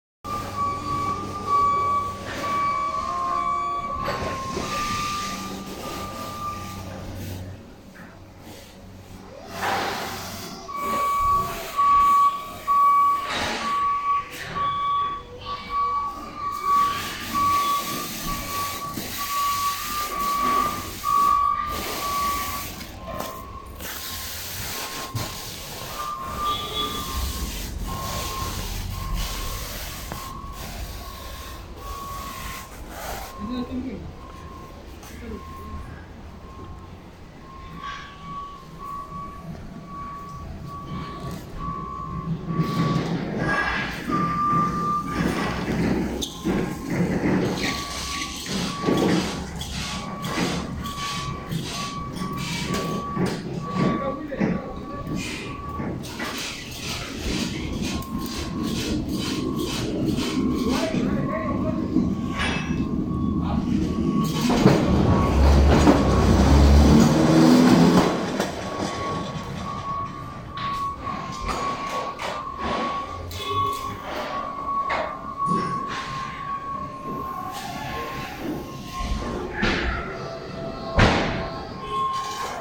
Por ello les invitamos a que nos envíen los sonidos más representativos de su comunidad.